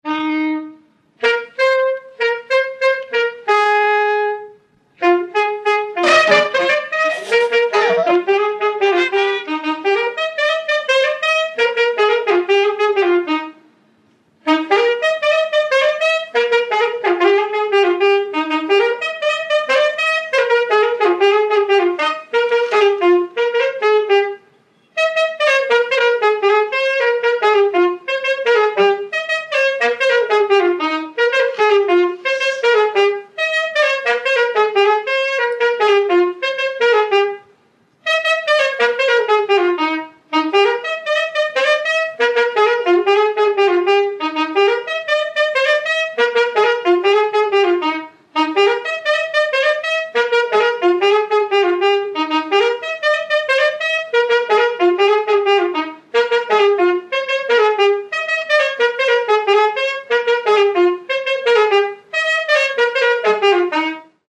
Peza de saxofón 1
Palabras chave: instrumental
Áreas de coñecemento: FESTAS
Soporte orixinal: Casete
Instrumentos: Saxofón